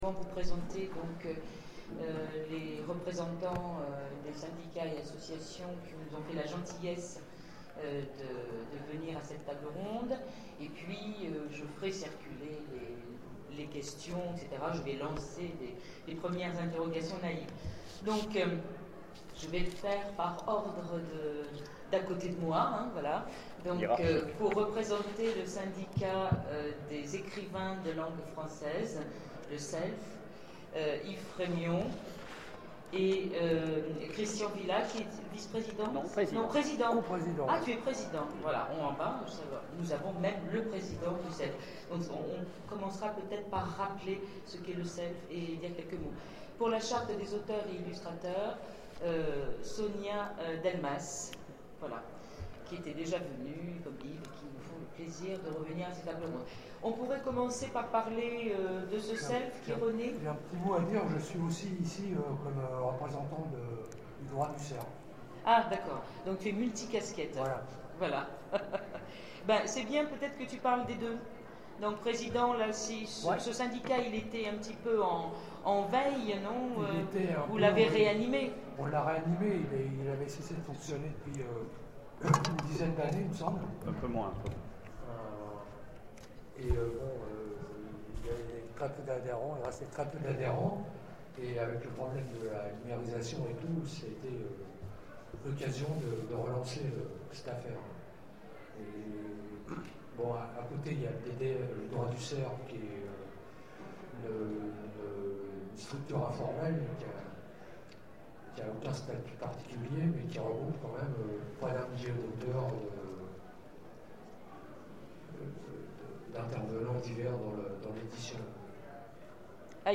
Zone Franche 2013 : Conférence Reprise des droits, ouvrages indisponibles et protection des oeuvres en ligne - ActuSF - Site sur l'actualité de l'imaginaire